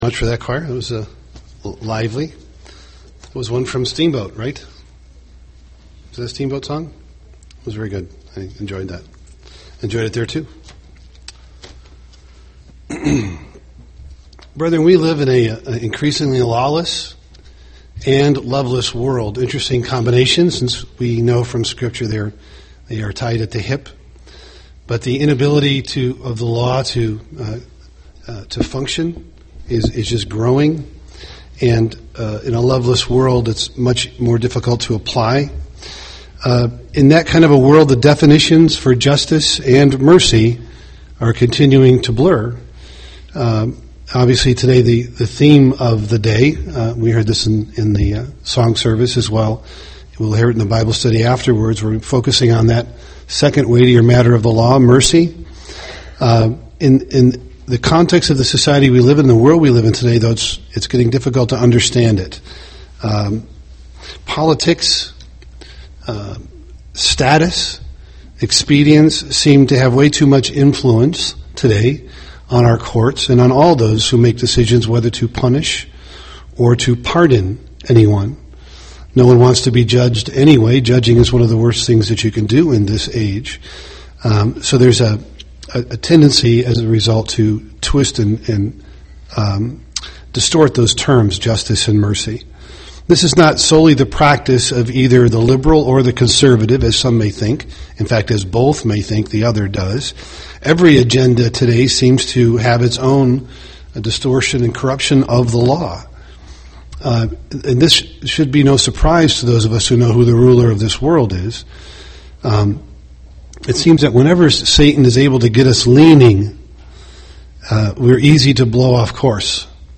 Given in Twin Cities, MN
UCG Sermon mercy law Studying the bible?